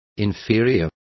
Complete with pronunciation of the translation of inferior.